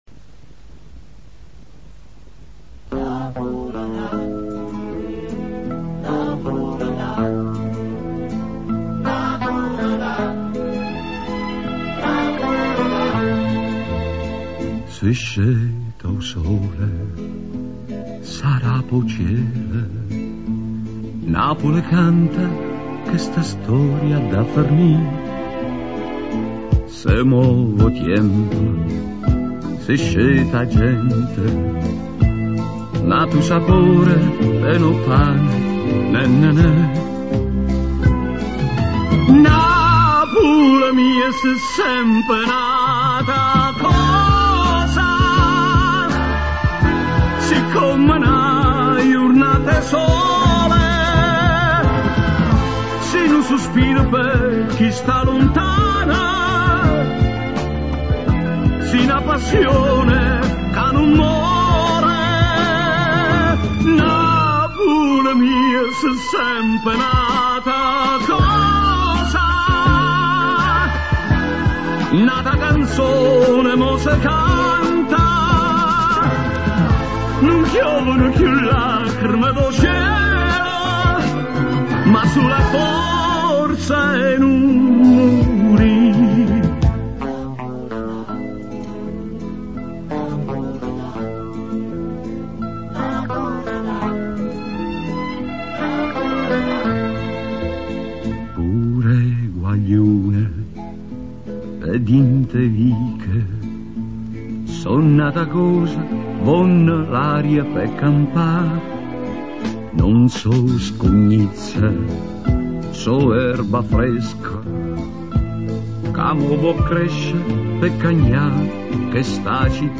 Обращаюсь к знатокам итальянской эстрады.
Ничего себе голосище!!!